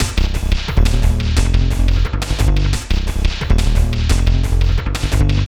B + D LOOP 2 2.wav